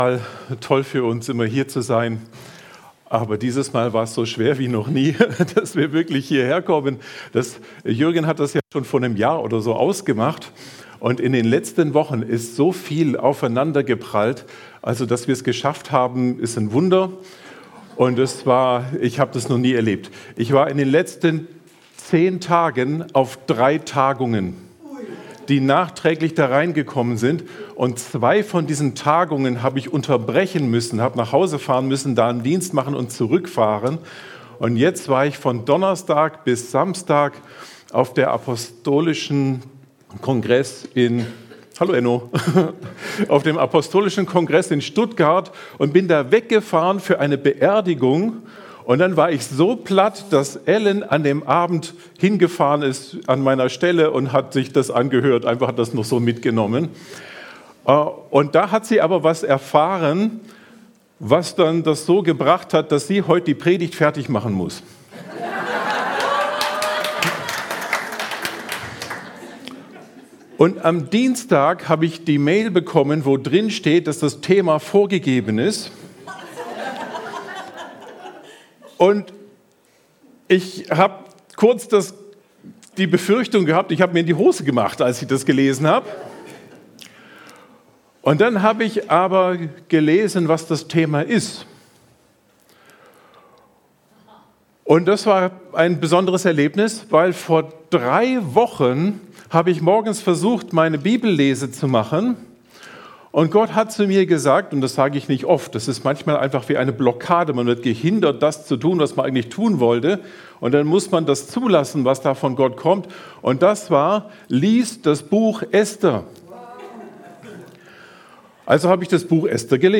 Predigten | Willkommen